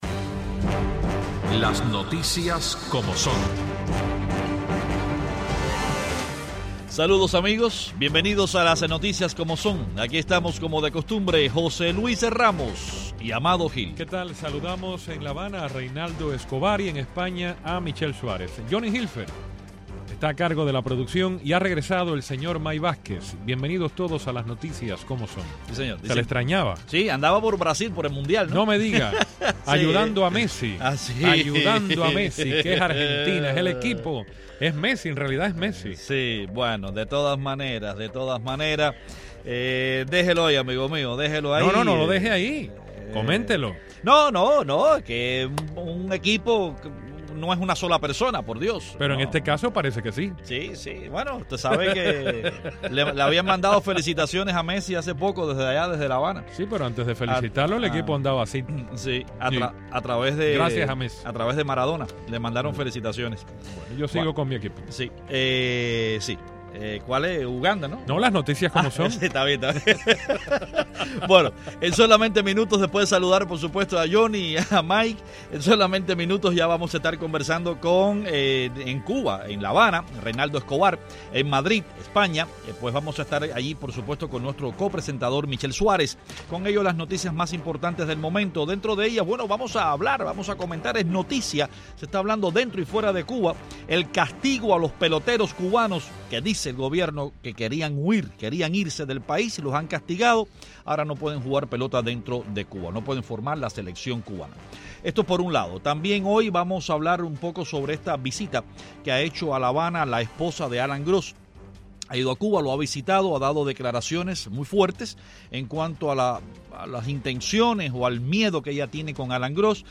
Los periodistas cubanos